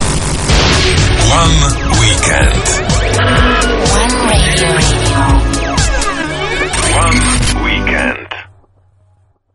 ktorá produkovala jingle aj pre Fun Radio